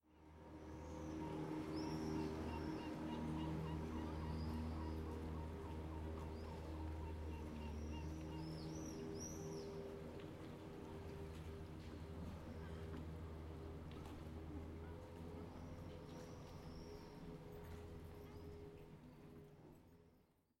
06/07/2016 10:00 Il n’y a pas de voitures à Venise : sans bruit et sans danger, la rue redevient un vecteur de lien social. Le bateau est aussi une extension paisible du foyer.